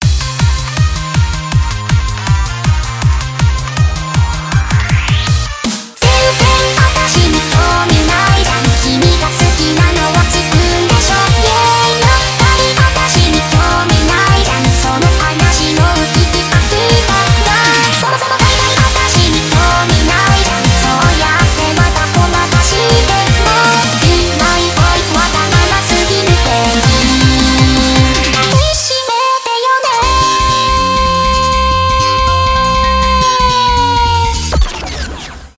electronic , танцевальные , энергичные , j-pop , быстрые